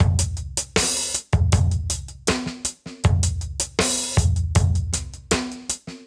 Index of /musicradar/sampled-funk-soul-samples/79bpm/Beats
SSF_DrumsProc2_79-01.wav